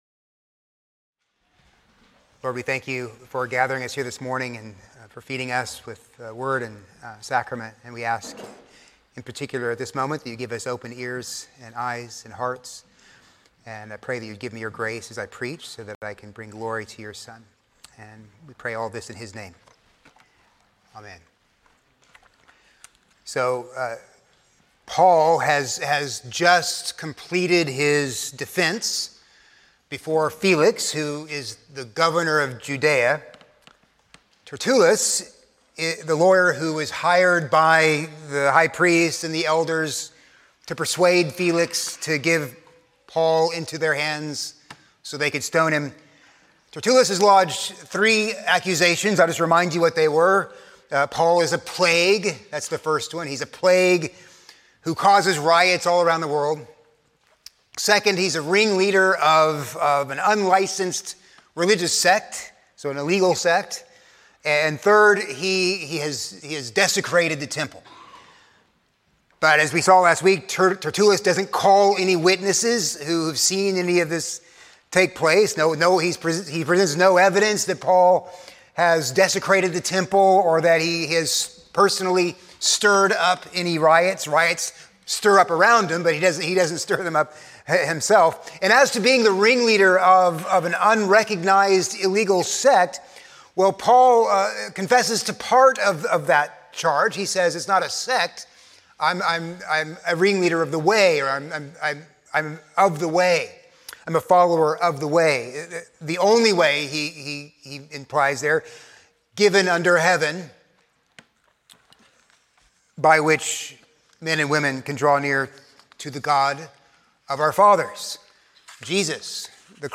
A sermon on Acts 24:22-27